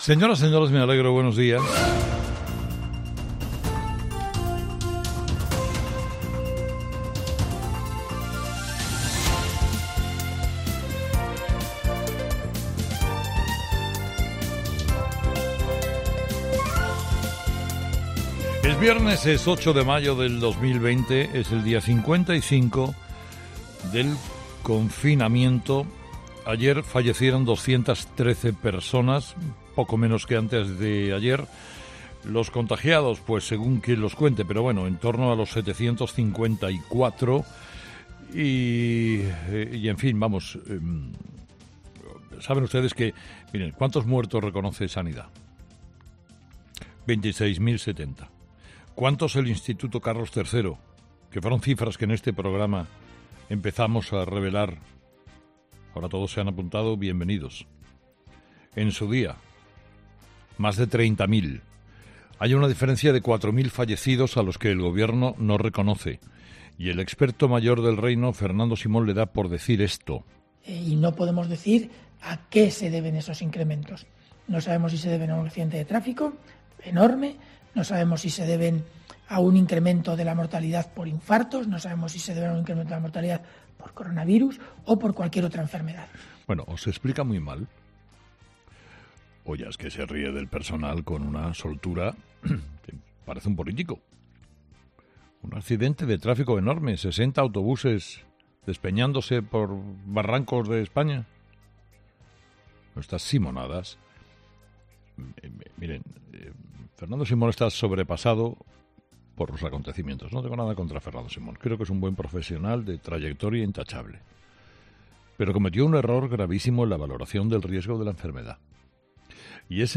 ESCUCHA EL EDITORIAL DE CARLOS HERRERA EN 'HERERRA EN COPE' Fernando Simón está sobrepasado por los acontecimientos O se explica muy mal o ya es que se ríe del personal con una soltura, parece un político.